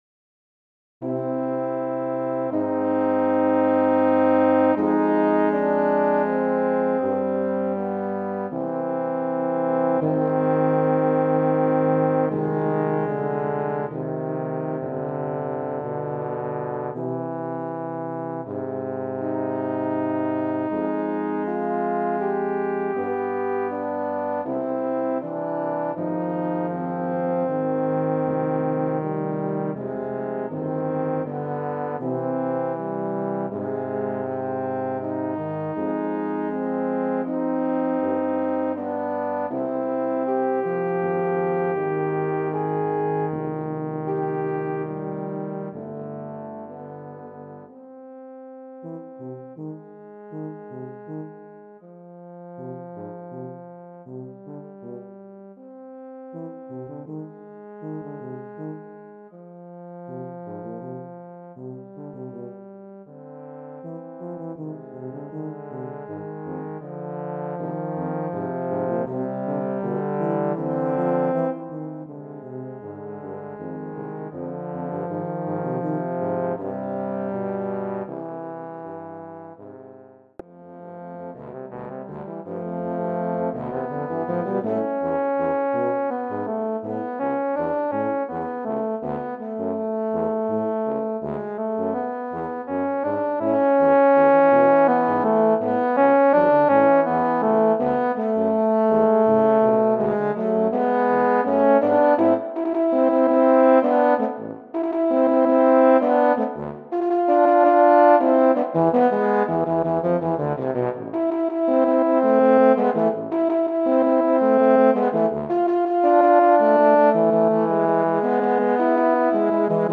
Voicing: Low Brass Trio